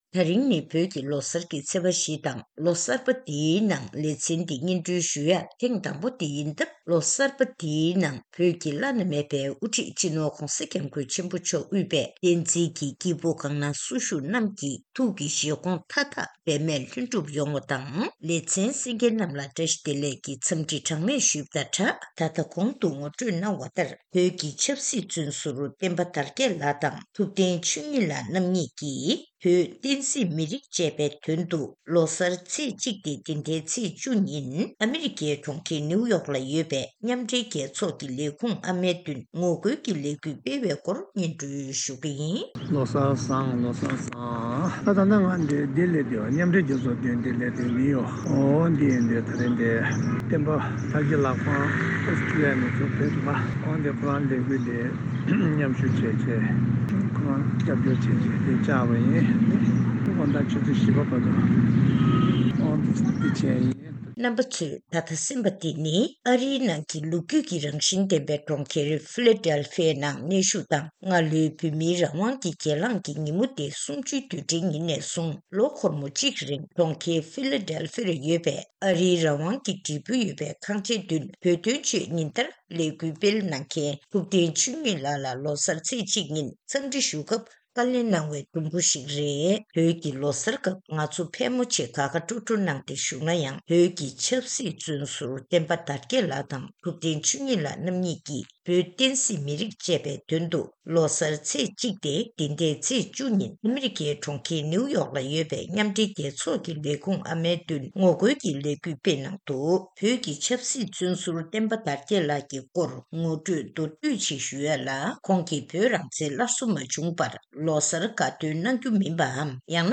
ཐེངས་འདིའི་གནས་འདྲིའི་ལེ་ཚན